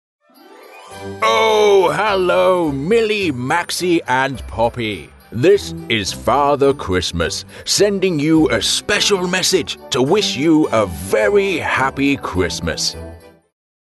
Positive Voice Over Artists | Voice Fairy